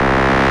plane.wav